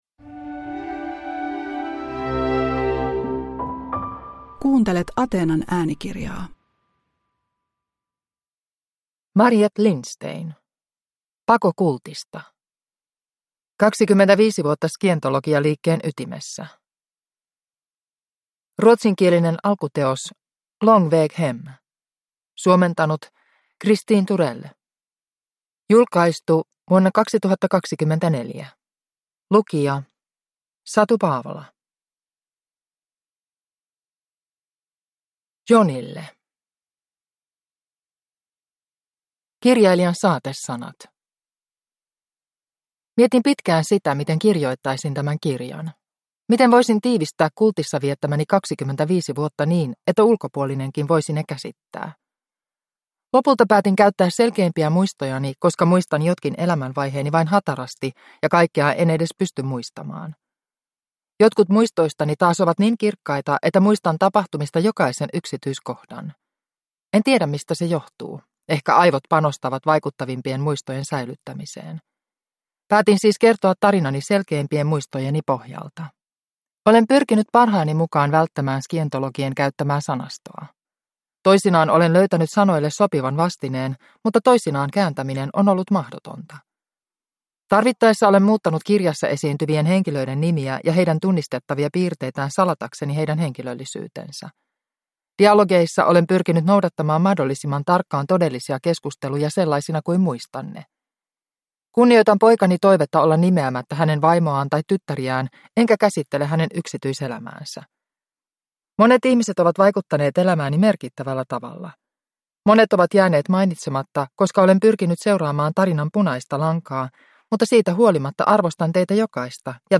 Pako kultista (ljudbok) av Mariette Lindstein | Bokon